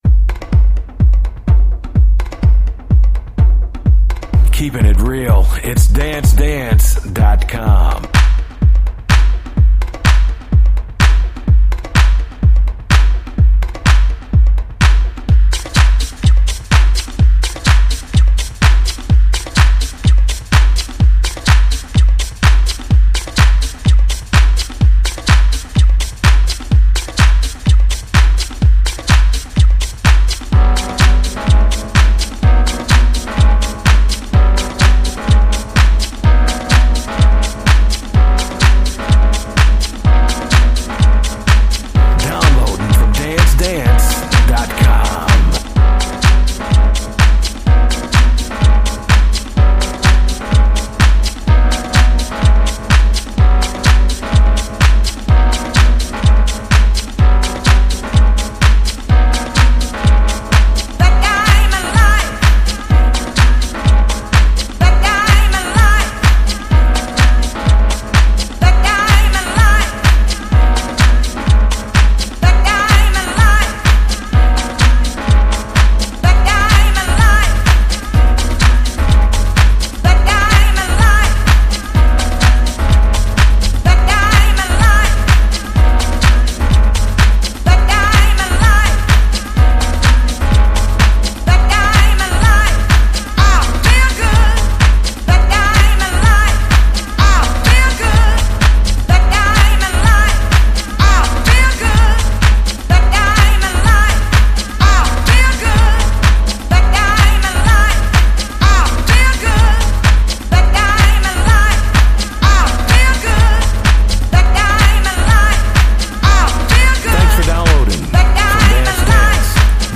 deep music
Funky House with a deep twist